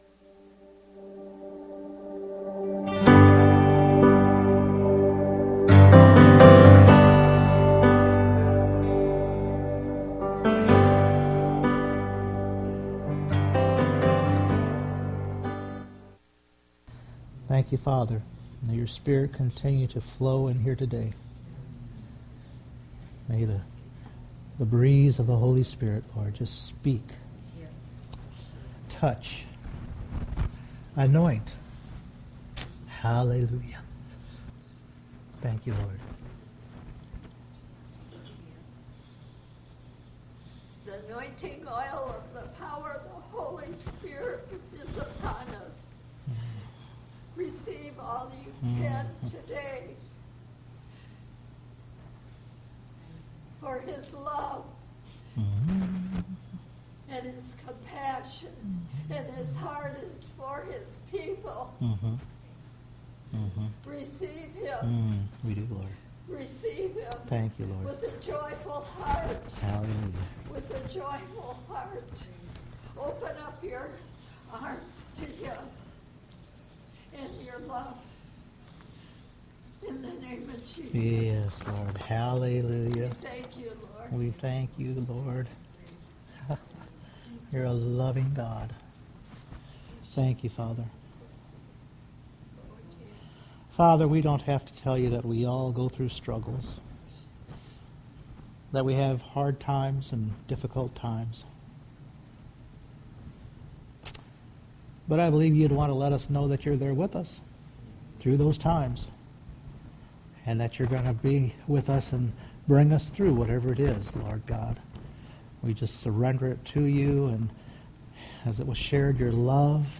Proverbs 4:23 Service Type: Sunday Morning Solomon shares in Proverbs that our heart directs our paths.